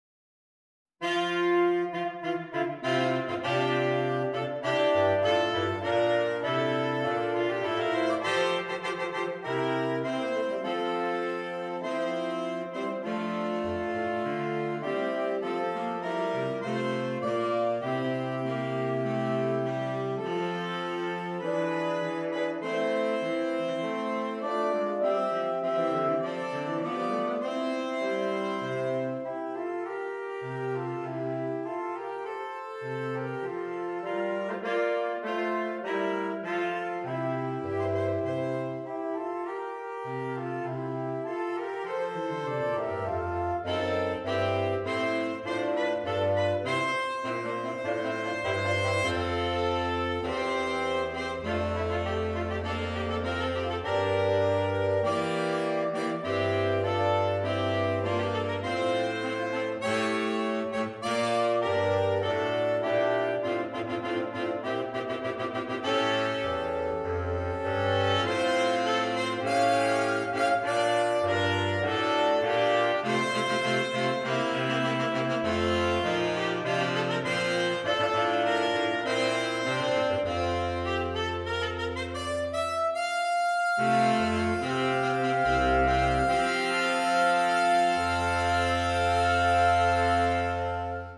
Saxophone Quartet (AATB)
It features some colorful contemporary harmonies.